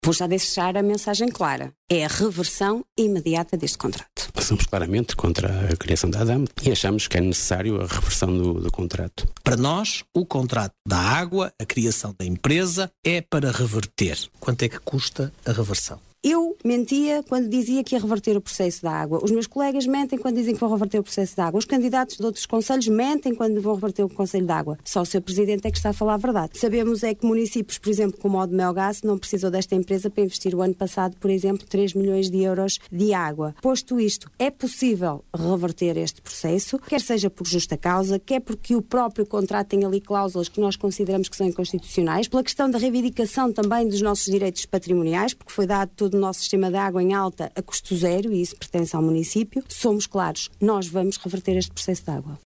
Debate Autárquicas 2021 – Rádio Caminha